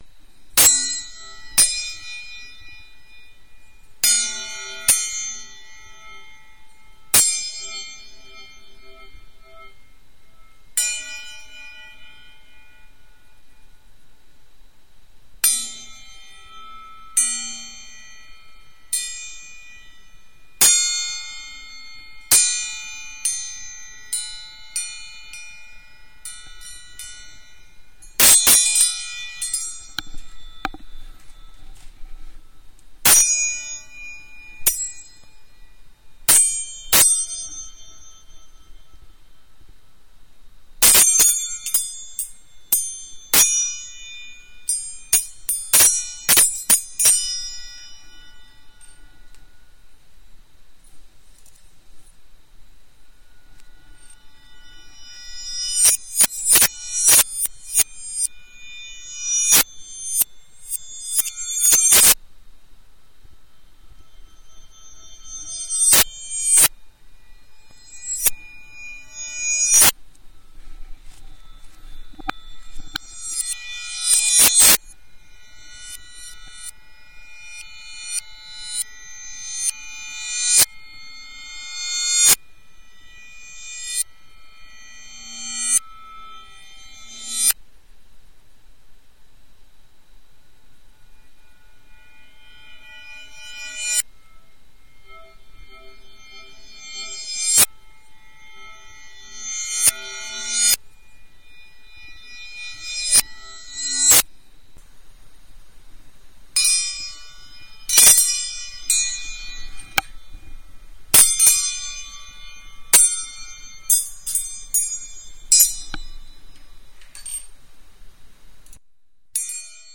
belldings
clang ding dings metal plates ting tones sound effect free sound royalty free Sound Effects